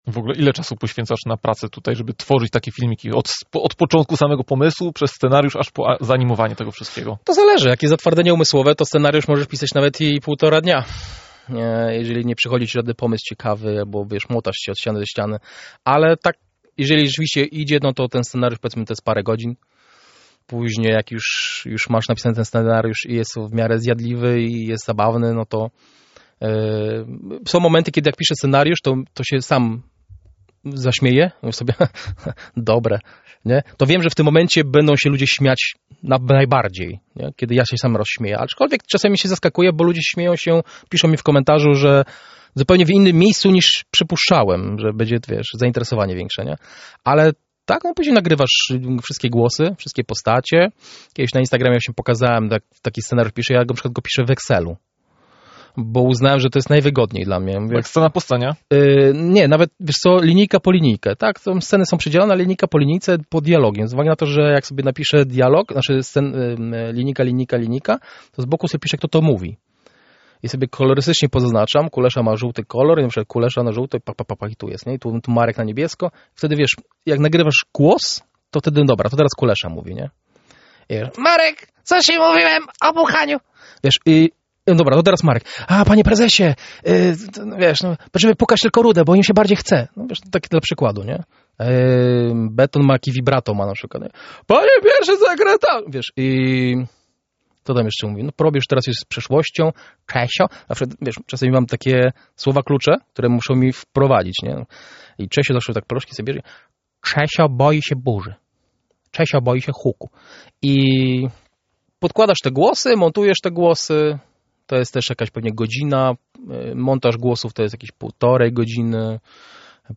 Porannej Rozmowie Radia Centrum. Wywiad – ze względu na długość – został podzielony na dwie części.